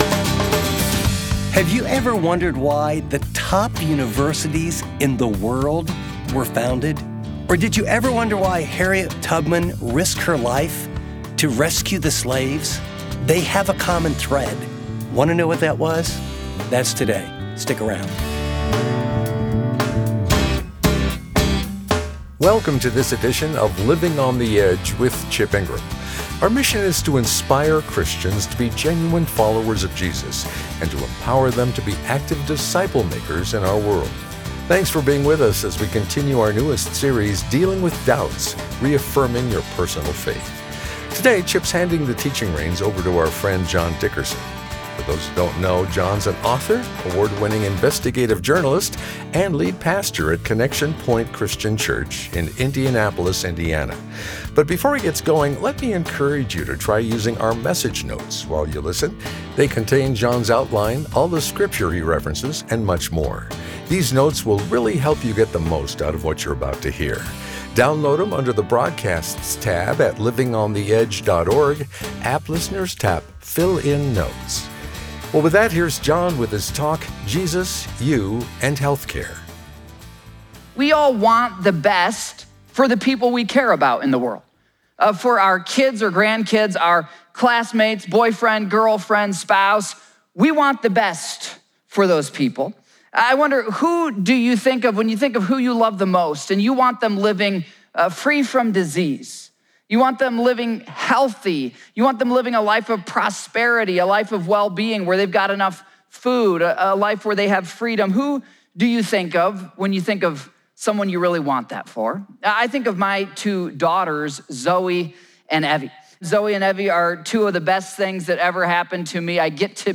In this program, guest teacher